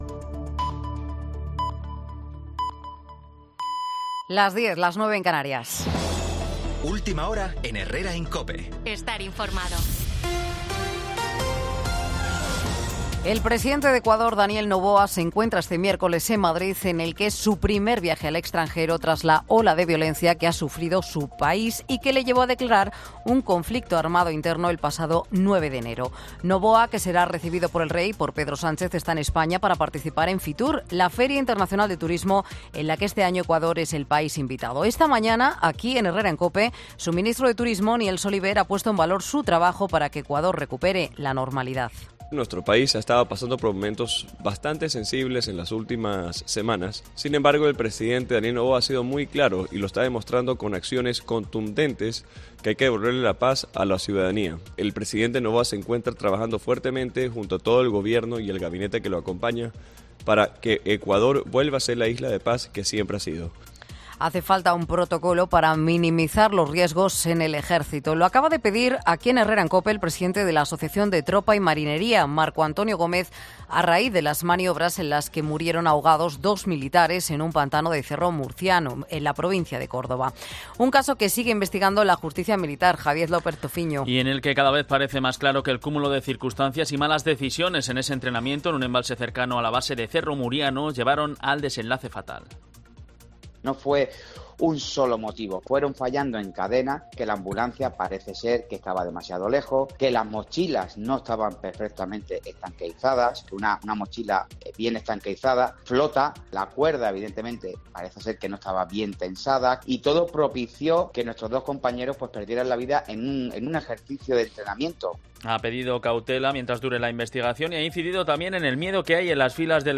Boletin de Noticias de COPE del 24 de enero del 2024 a las 10 horas